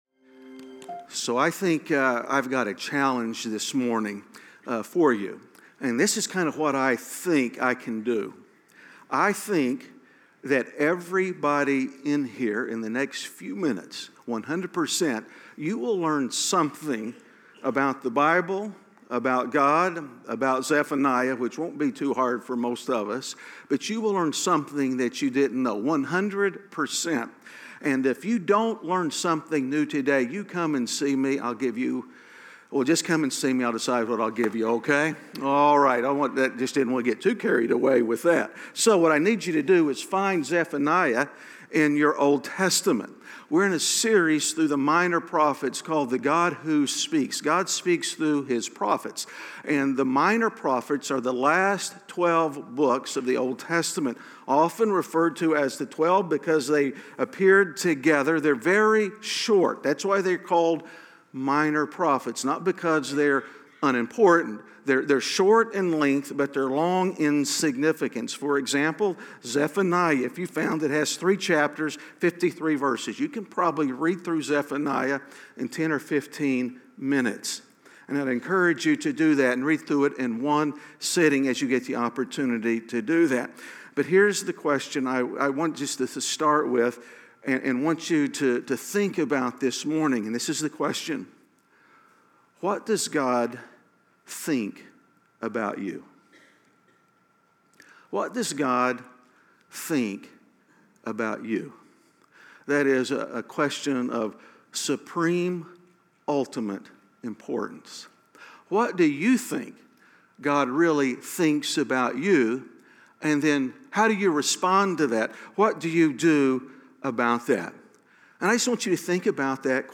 A message from the series "The God Who Speaks." The Prophet Micah shows us that God loves to use no-bodies from no-where.